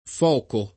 foco [ f 0 ko ]